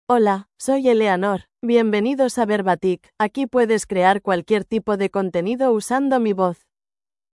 EleanorFemale Spanish AI voice
Eleanor is a female AI voice for Spanish (Spain).
Voice sample
Female
Eleanor delivers clear pronunciation with authentic Spain Spanish intonation, making your content sound professionally produced.